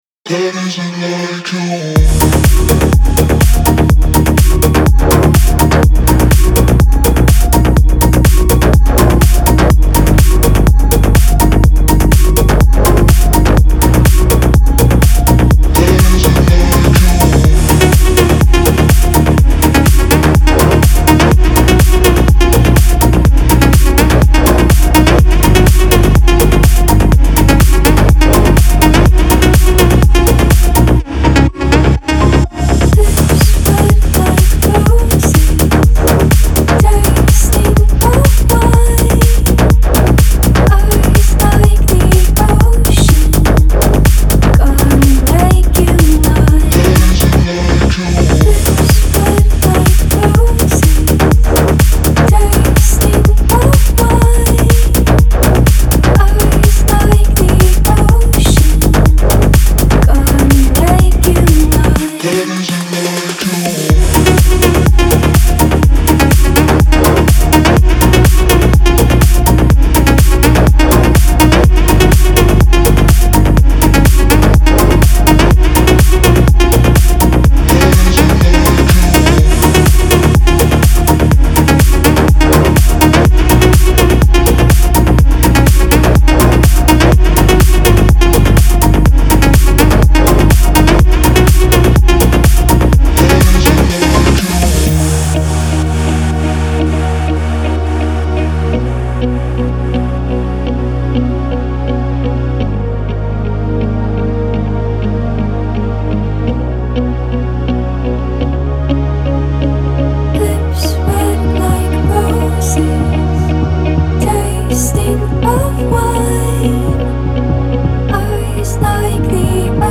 dance
эстрада , диско
pop